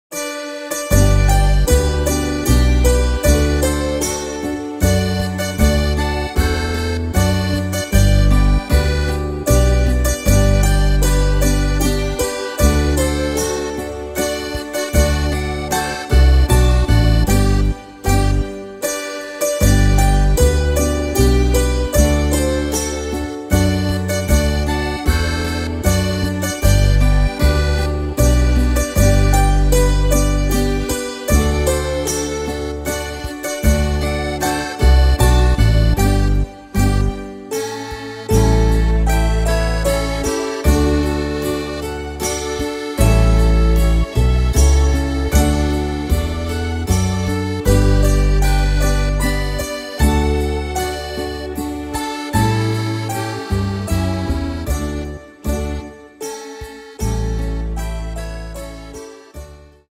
Tempo: 77 / Tonart: G-Dur